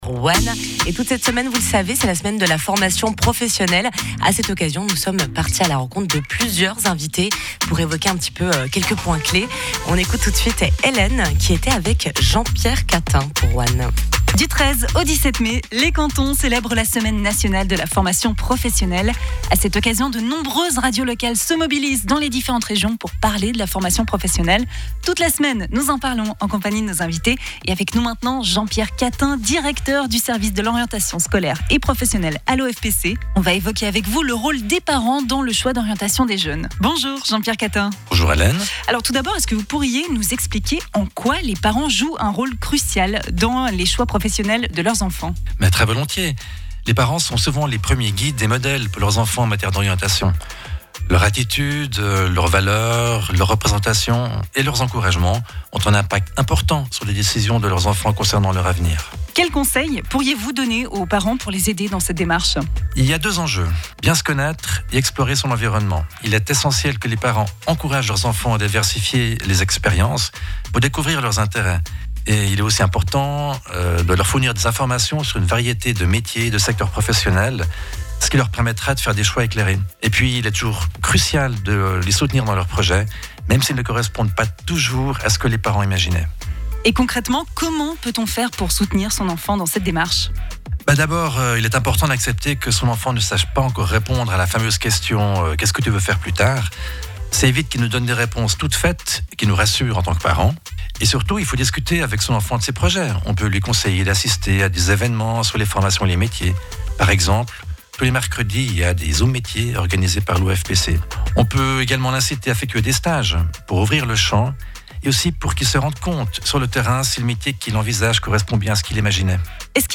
Dans cet épisode, notre invité aborde le sujet de l’influence des parents dans les choix professionnels de leur(s) enfants(s).